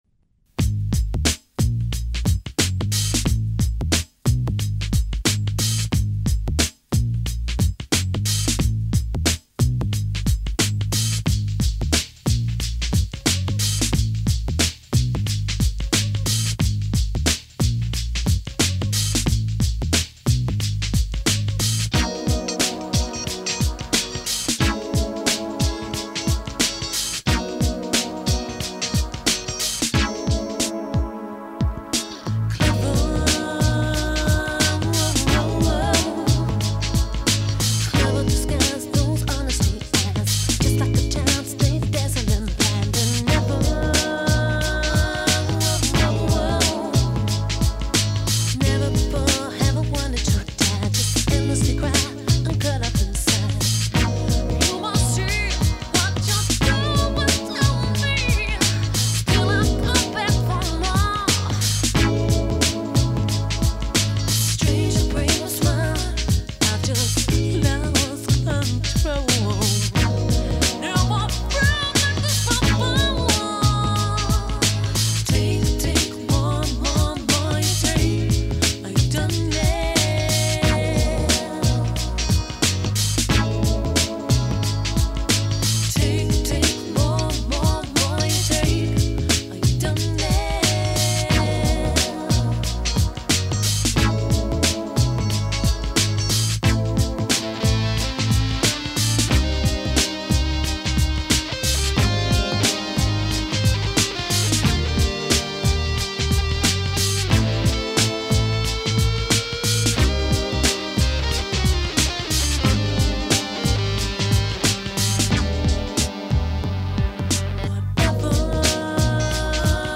Here are some demo tapes that survived from back in the days...